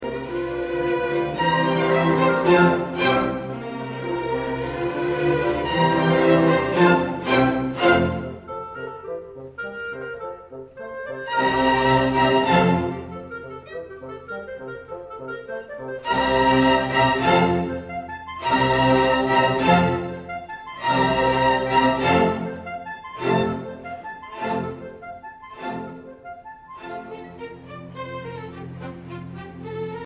The winds were mostly ringers (including me), but the strings were at high school level.
Beethoven: Symphony No. 6, First Movement by the Austin Youth Orchestra